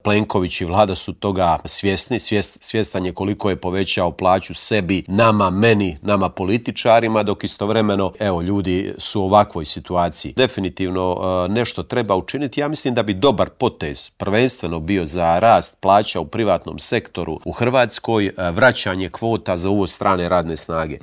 Kritizirao je premijera Andreja Plenkovića poručivši da Hrvatska gubi 10 milijardi eura godišnje zbog korupcije.